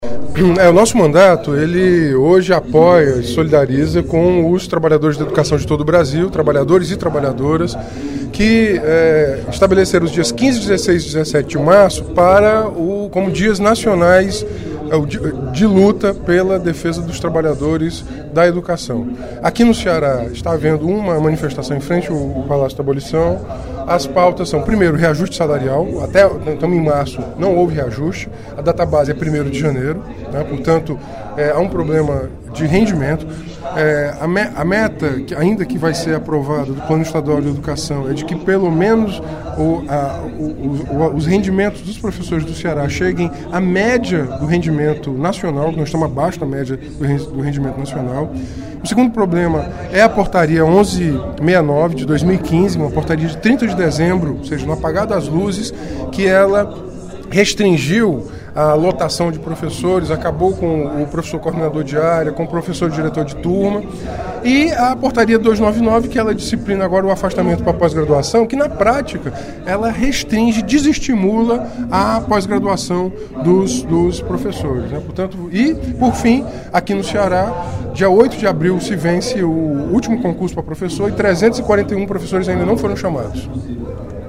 O deputado Renato Roseno (Psol) se solidarizou, em pronunciamento no primeiro expediente da sessão plenária desta terça-feira (15/03), com os trabalhadores da educação de todo o Brasil, pelos três dias de luta nacional - 15, 16 e 17 de março - em defesa da categoria.